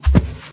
sounds / mob / cow / step4.ogg